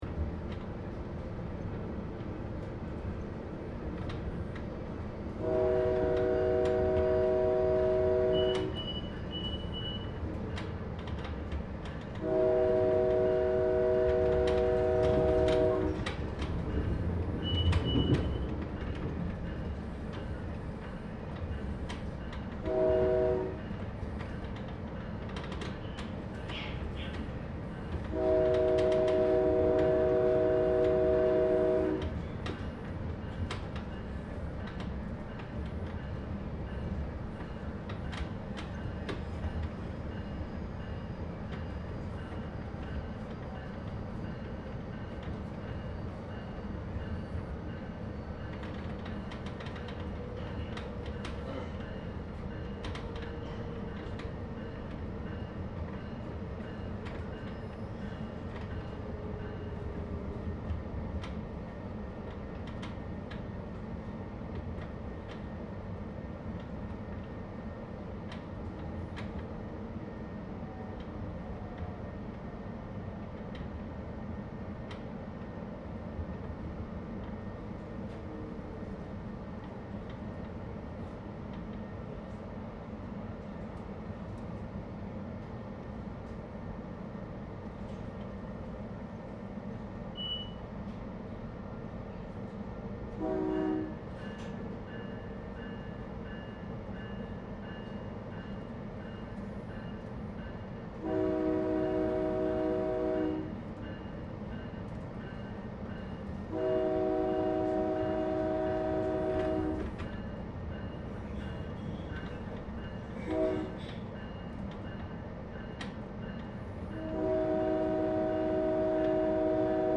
Train from Denver Airport to Union Station
Part of the Until We Travel project to map and reimagine the sounds of transport and travel in a pre-pandemic and pandemic world.